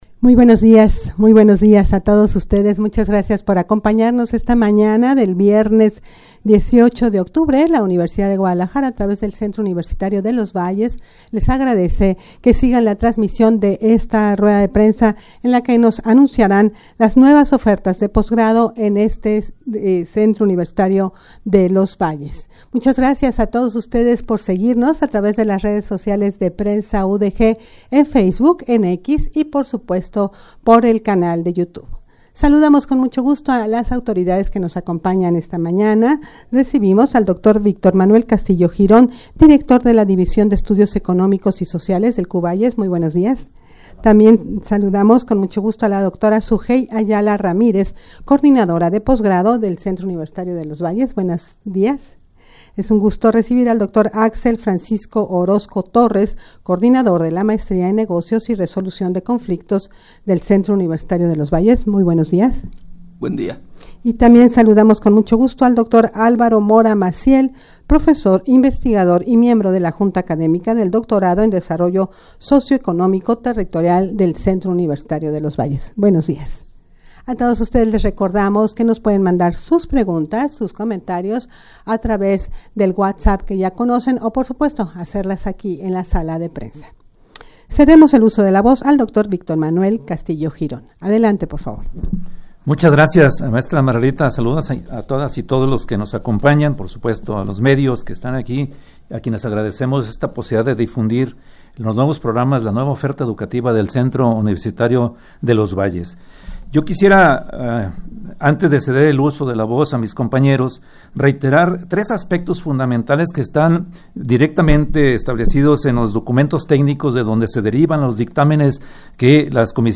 rueda-de-prensa-para-dar-a-conocer-dos-nuevas-ofertas-de-posgrado-en-este-campus.mp3